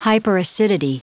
Prononciation du mot hyperacidity en anglais (fichier audio)
Prononciation du mot : hyperacidity